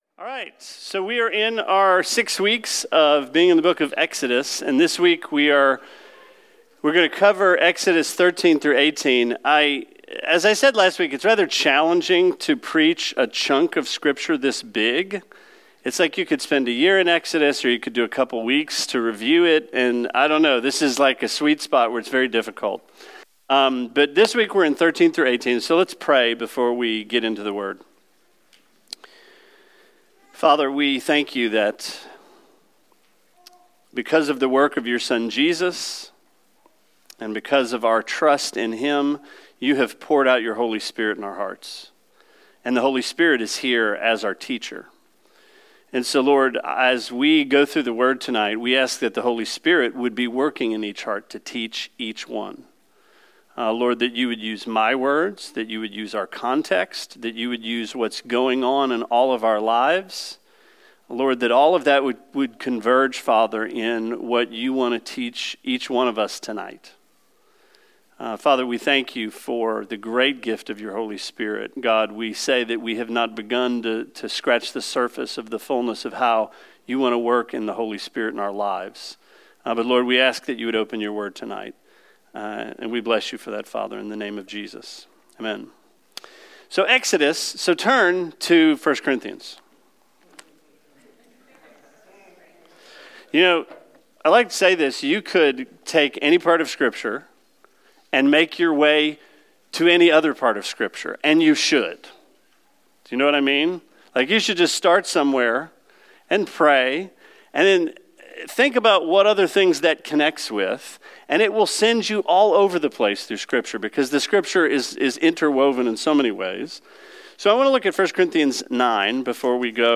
Sermon 06/21: Exodus 13-18